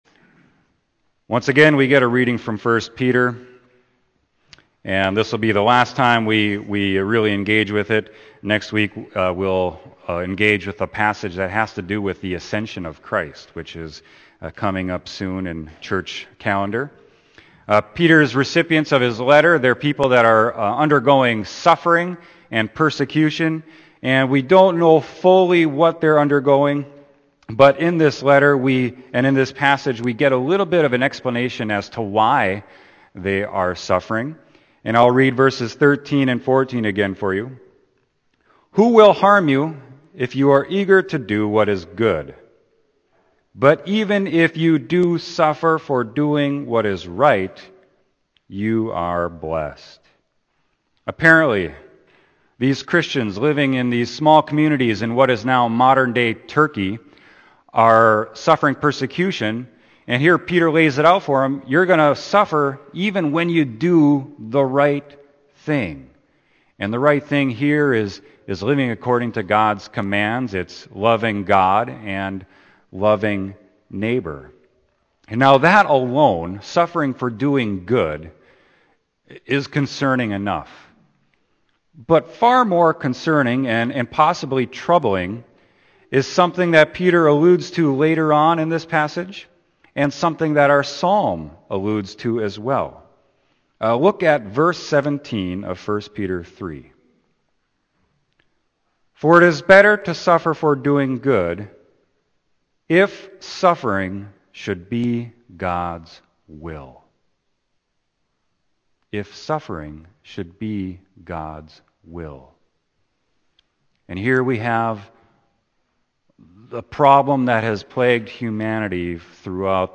Sermon: 1 Peter 3.13-22